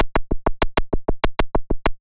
disarm_multi_b.wav